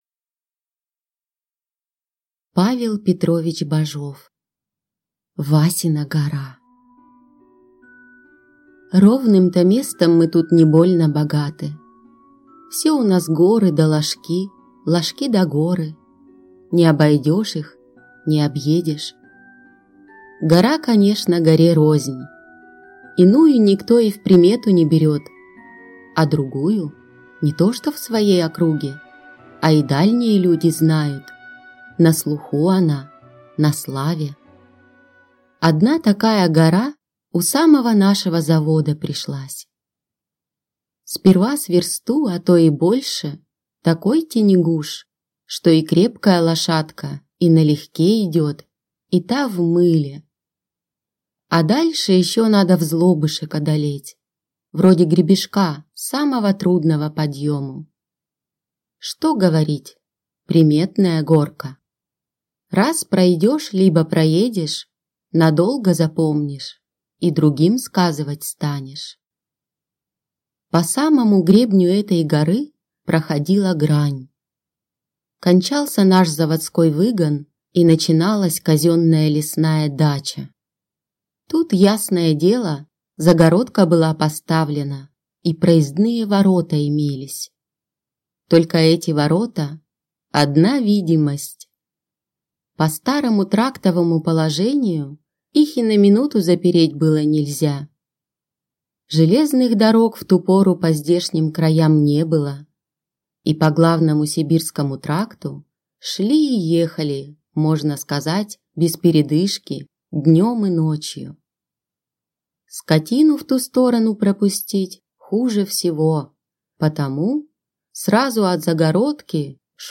Аудиокнига Васина гора | Библиотека аудиокниг